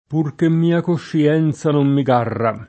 p2r ke mm&a košši-$nZa nom mi g#rra] (Dante); Con Amor, con madonna et meco garro [